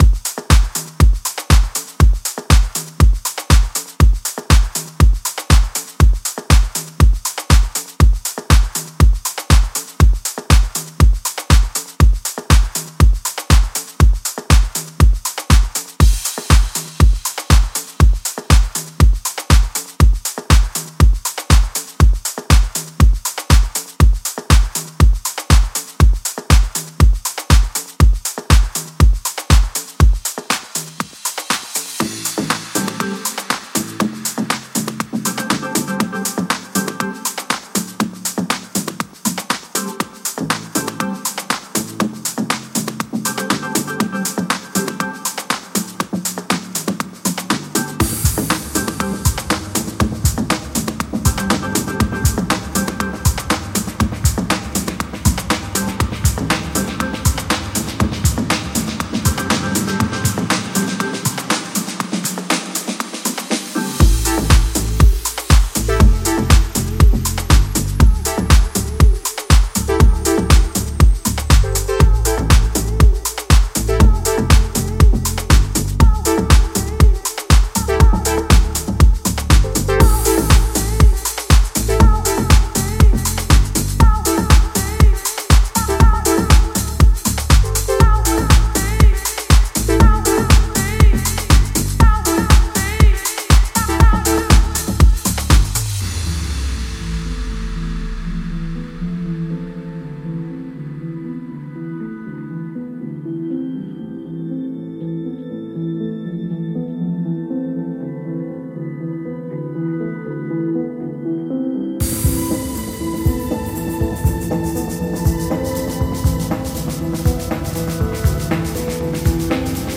brand new remix